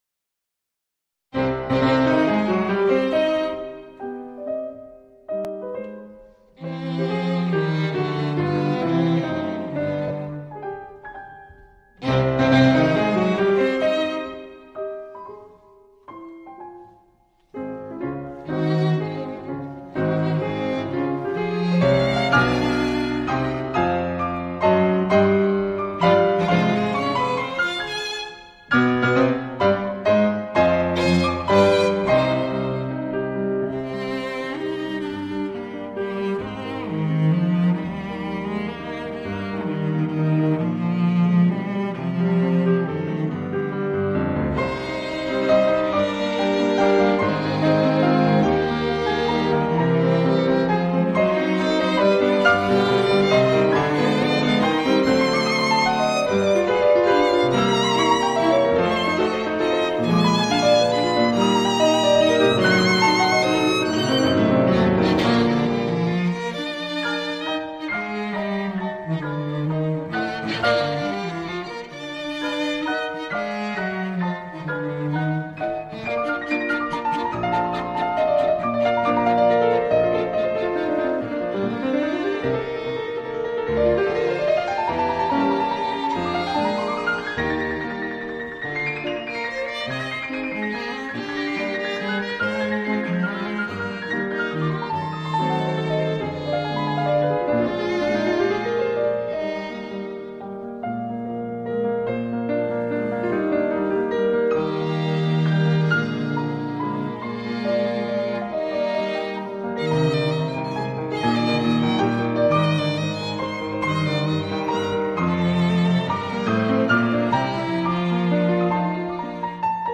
Soundbite 1st Movt